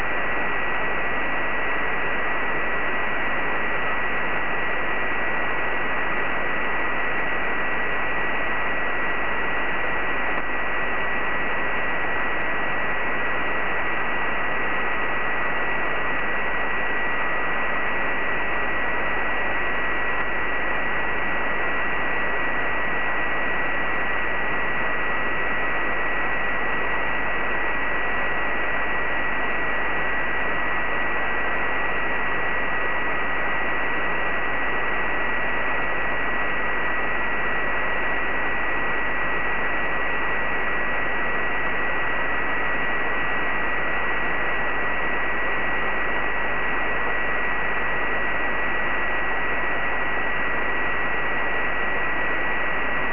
Mode: JT65B